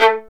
Index of /90_sSampleCDs/Roland - String Master Series/STR_Violin 1-3vb/STR_Vln2 % marc